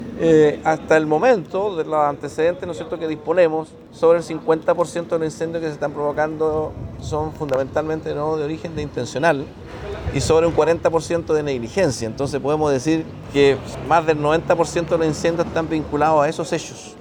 El delegado presidencial subrogante del Bío Bío, Humberto Toro, dijo que en la zona el 50% de los incendios forestales tienen un origen intencional. Del restante, el 40% responde a descuidos, ya sea por el uso de herramientas o por mal uso del fuego en días de riesgo.